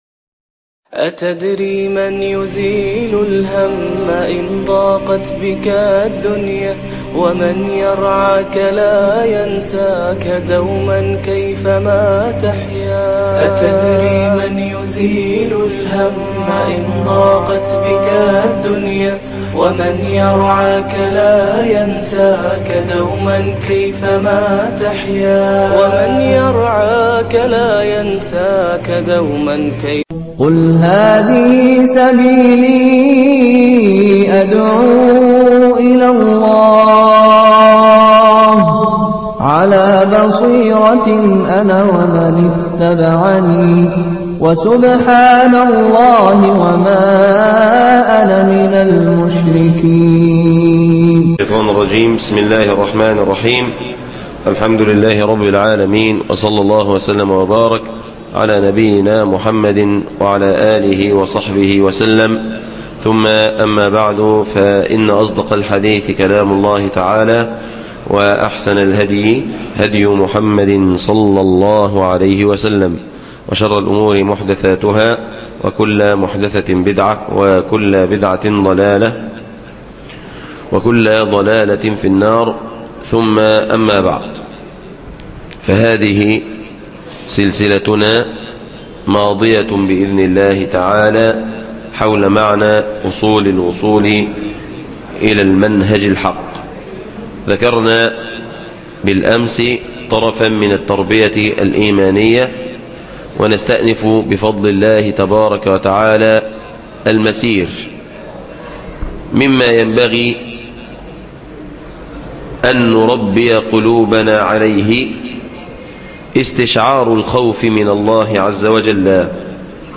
الدرس الرابع من سلسلة أصول الوصول إلى المنهج الحق - 25 رمضان 1433 بمسجد السلاب